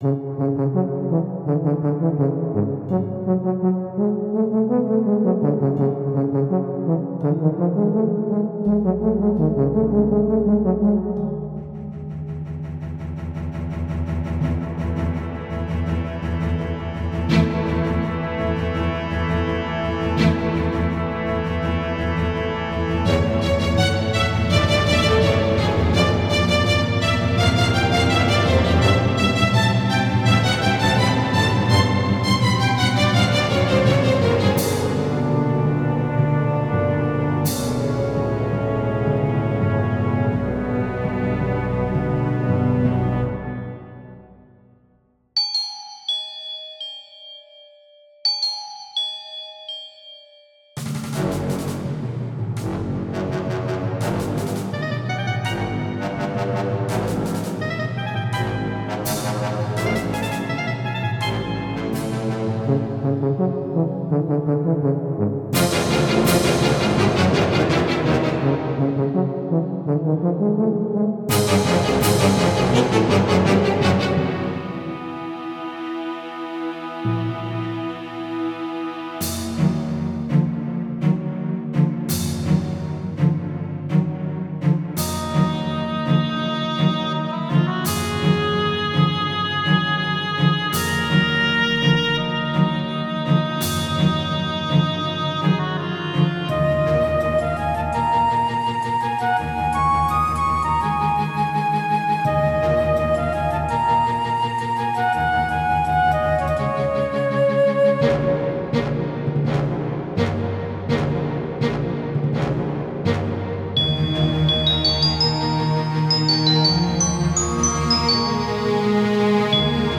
First somewhat serious attempts at making an orchestral theme
Right now the song is not finished, it's very all over the place and I'm looking to connect the various parts better.